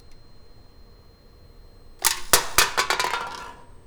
popcan_drop.wav